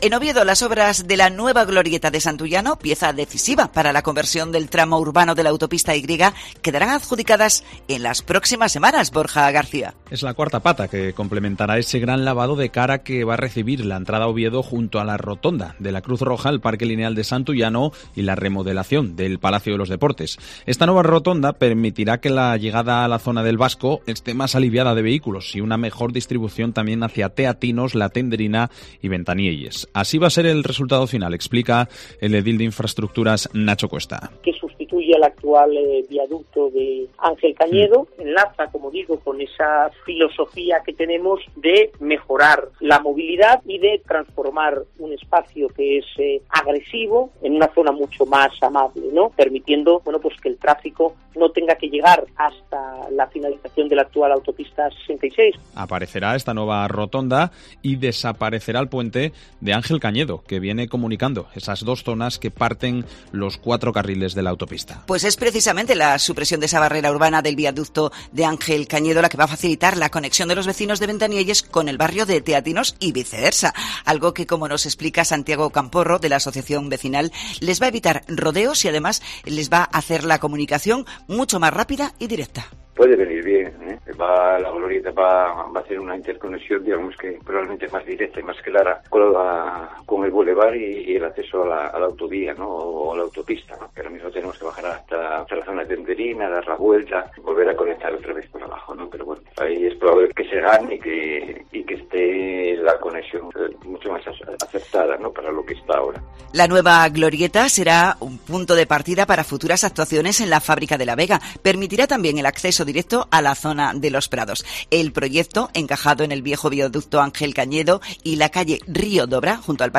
El resultado final y el propósito lo explica Nacho Cuesta, edil de Infraestructuras: "Sustituirá al actual viaducto.